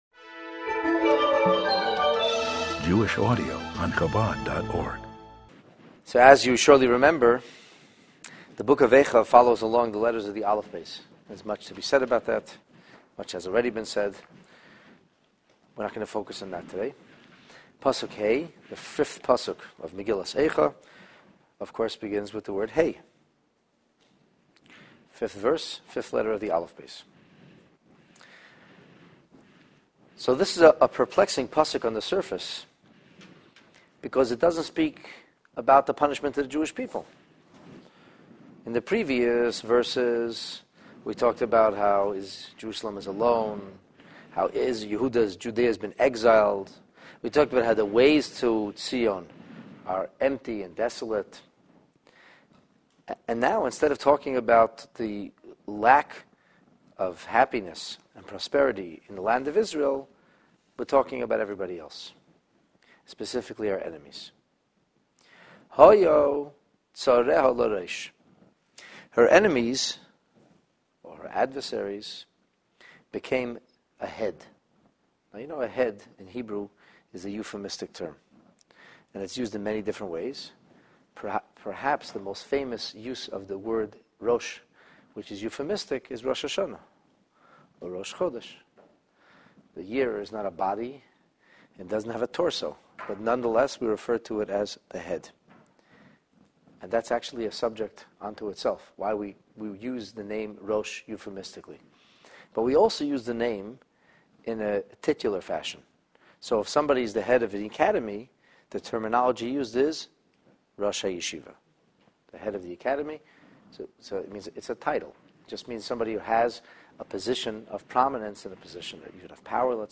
This text-based class on Megillat Eicha focuses on verse 5 of the first chapter.